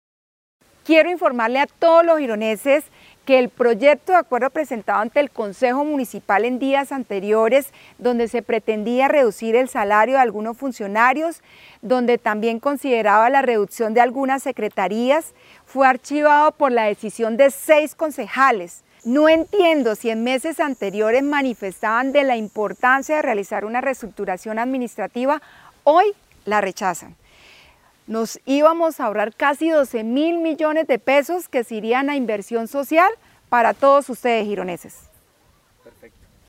Yulia Rodríguez, Alcaldía de Girón.mp3